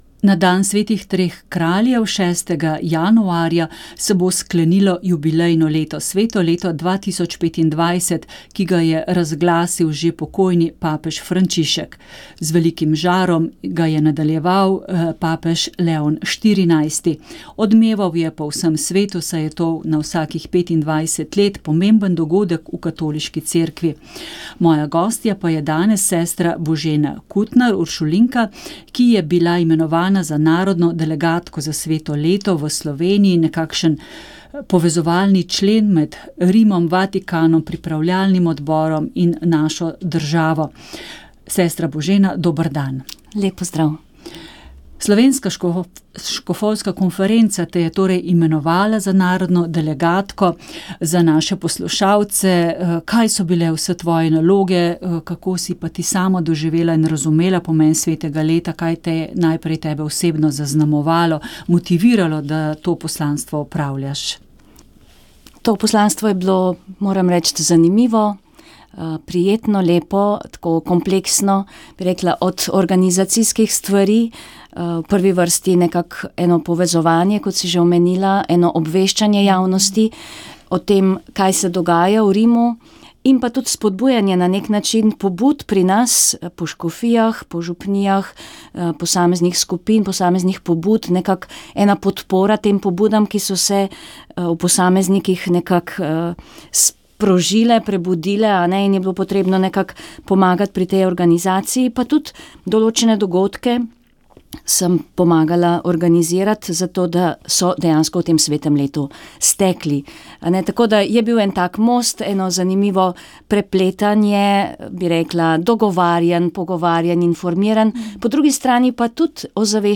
Govor
v Kočevskem rogu